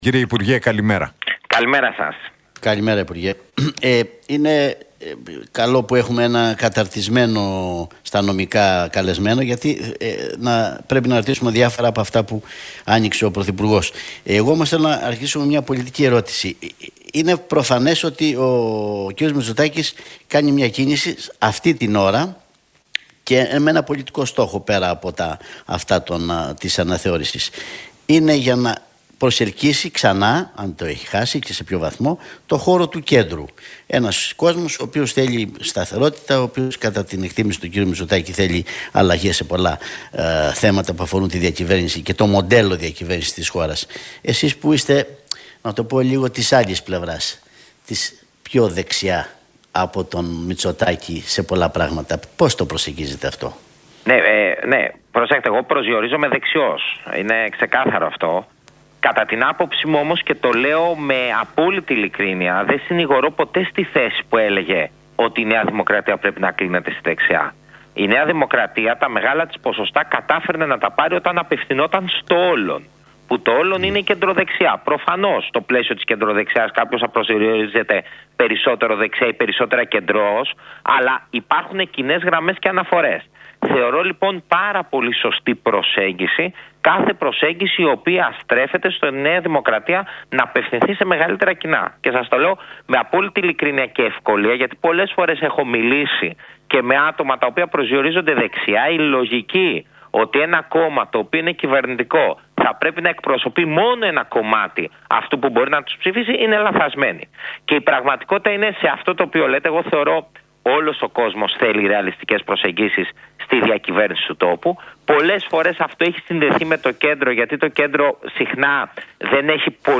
Για την πρόταση της Συνταγματικής Αναθεώρησης μίλησε ο υπουργός Μετανάστευσης και Ασύλου, Θάνος Πλεύρης στον Realfm 97,8 και τους Νίκο Χατζηνικολάου και